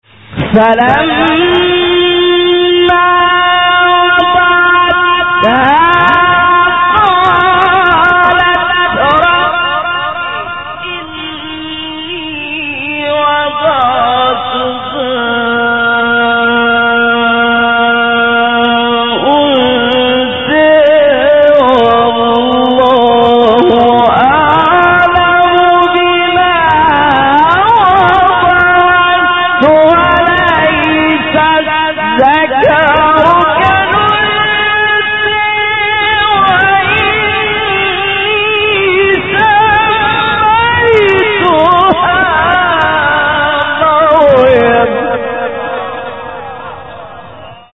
سوره : آل عمران آیه : 36 استاد : محمد لیثی مقام : حجاز قبلی بعدی